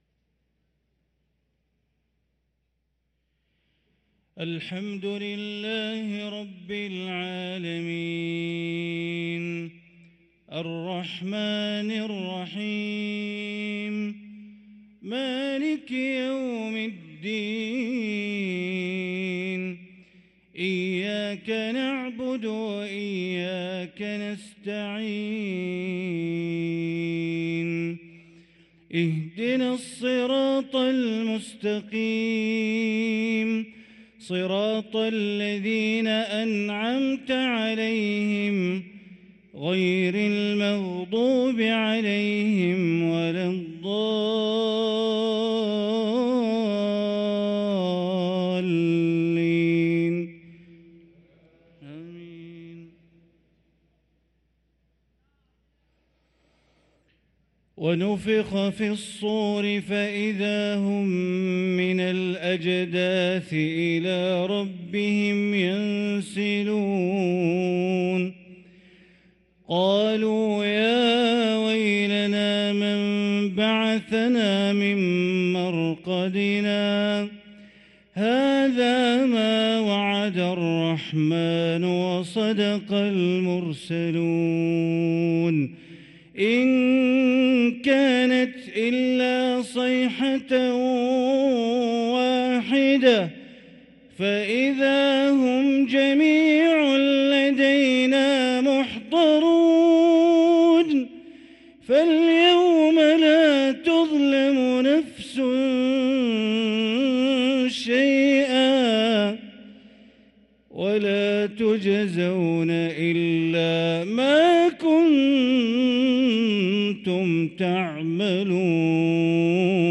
صلاة العشاء للقارئ بندر بليلة 29 ربيع الأول 1445 هـ
تِلَاوَات الْحَرَمَيْن .